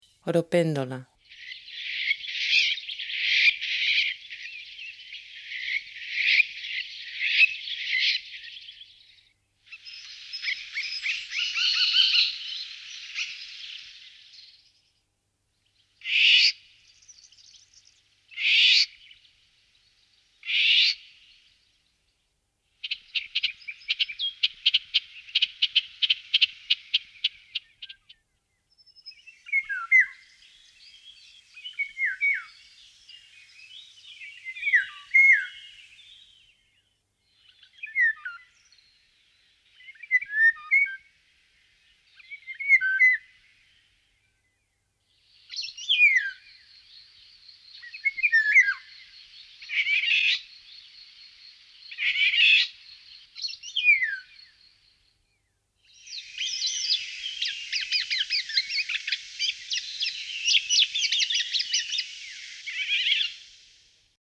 13_demo_3-54_Oropéndola.mp3